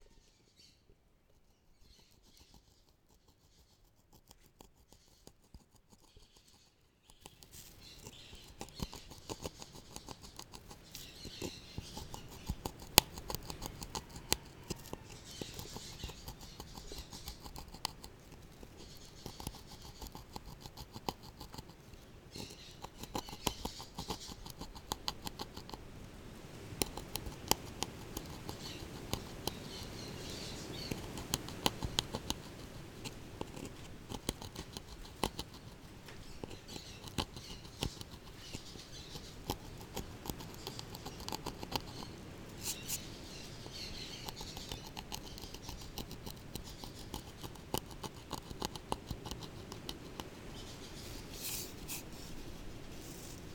Limpiando un jicaro para hacer un guacal GUANACASTE